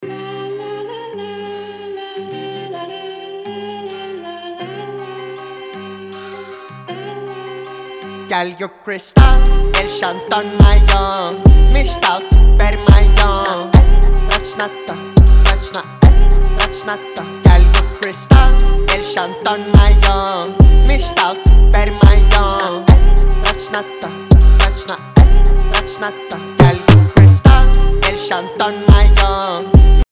• Качество: 320, Stereo
русский рэп